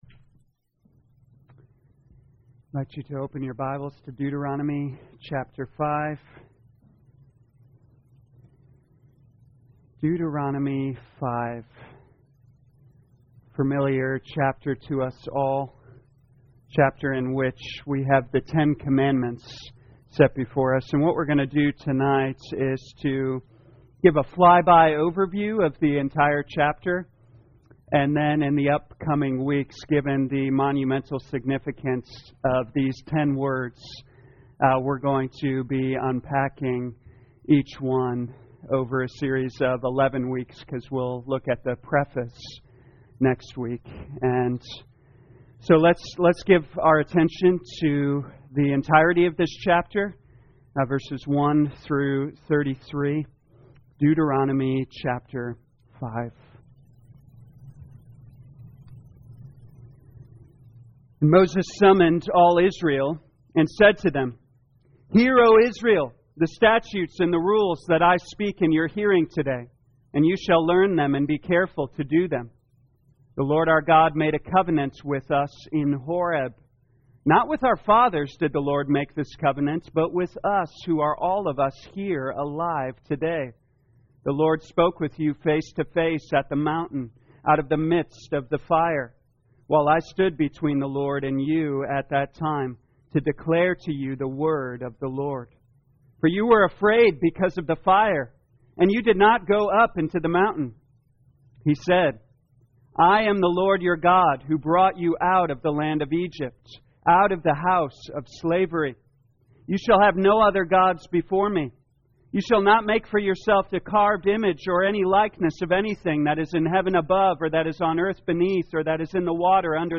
2021 Deuteronomy The Law Evening Service Download